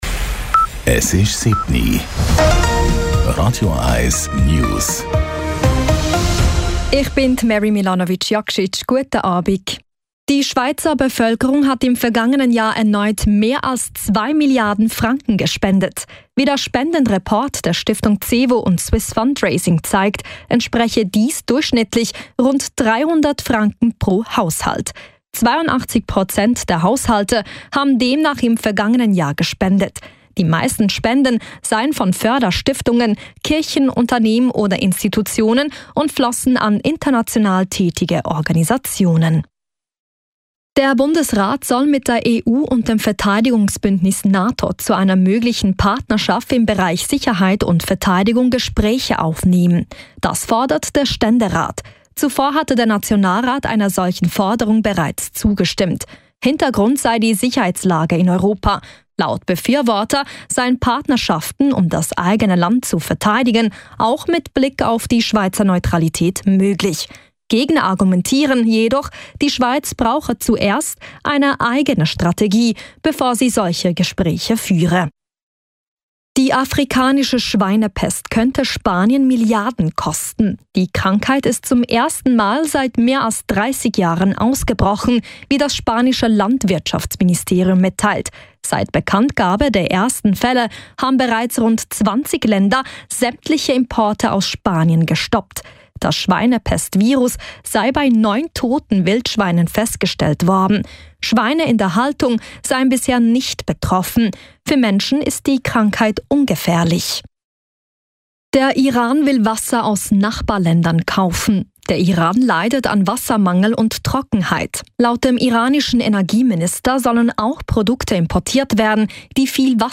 Die aktuellsten News von Radio 1 - kompakt, aktuell und auf den Punkt gebracht.
Nachrichten & Politik